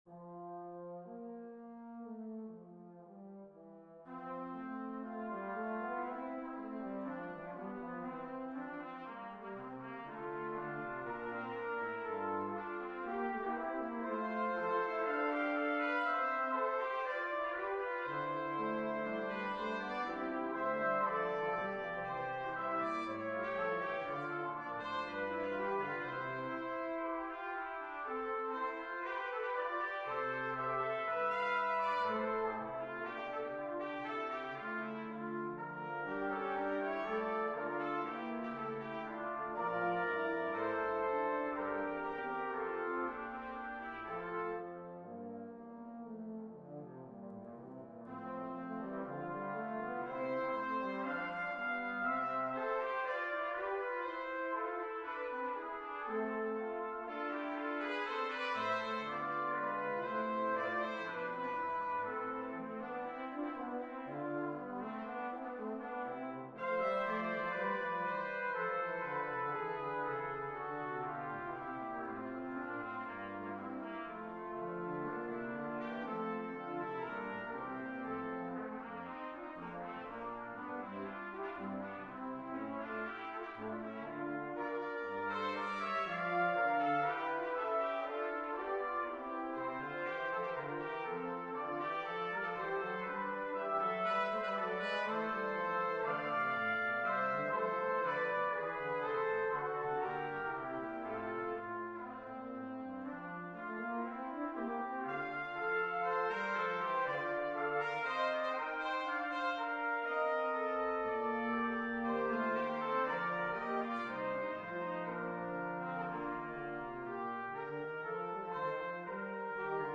Brass Ensembles
2 Trumpets, 2 Trombones